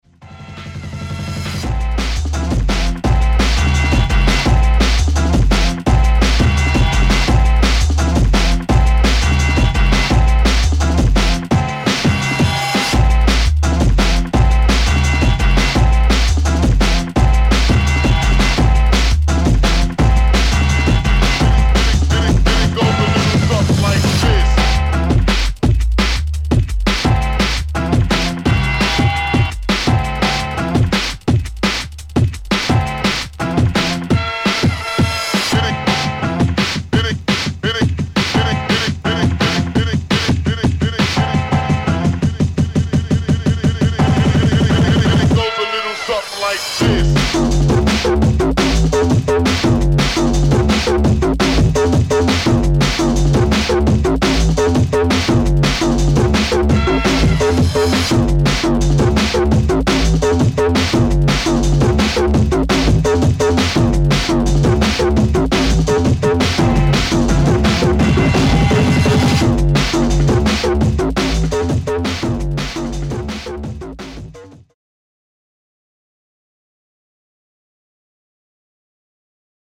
Jungle/Drum n Bass
Jump Up